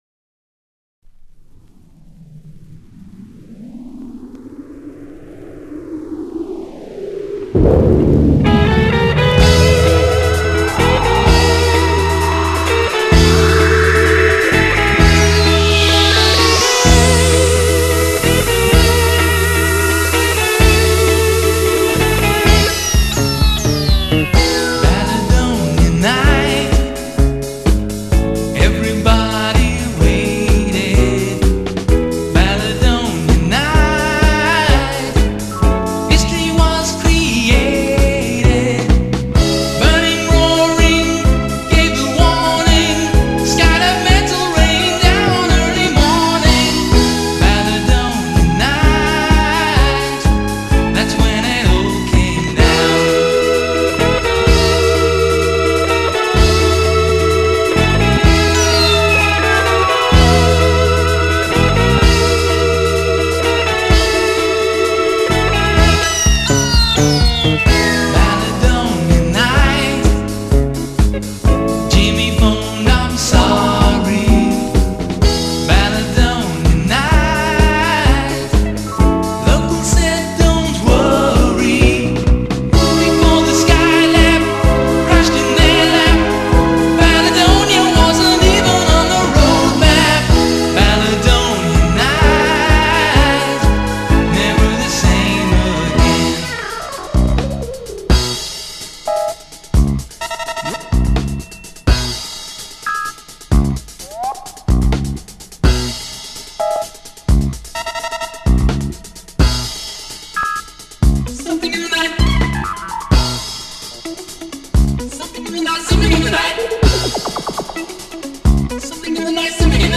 top Australian Christian singing group